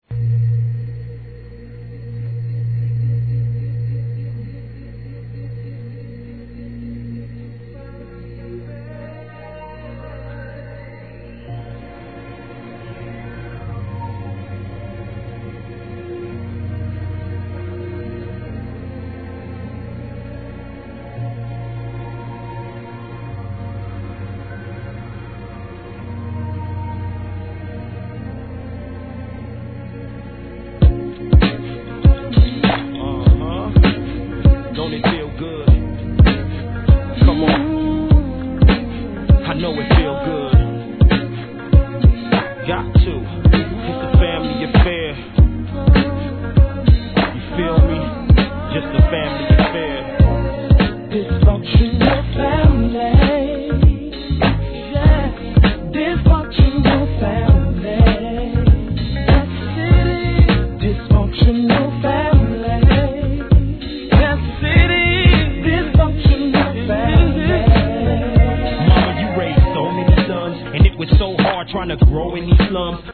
G-RAP/WEST COAST/SOUTH
使いのメロ〜･ナンバー!!